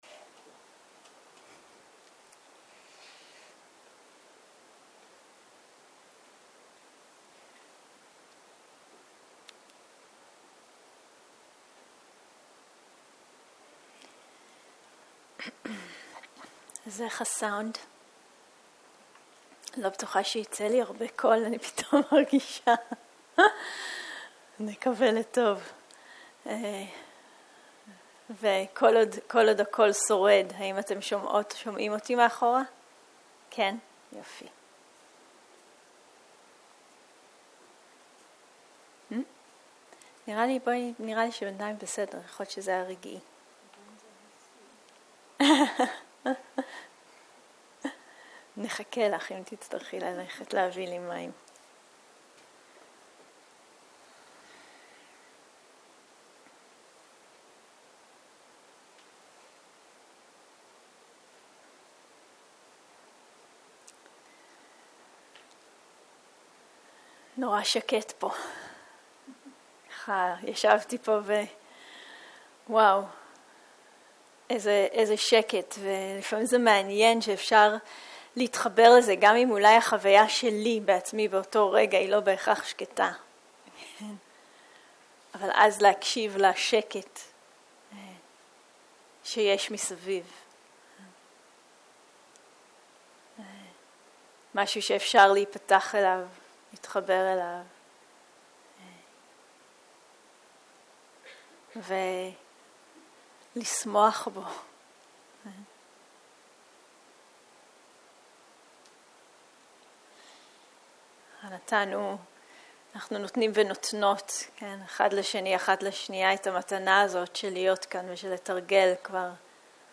ערב - שיחת דהרמה - ערפילים של מטא | תובנה
סוג ההקלטה: שיחות דהרמה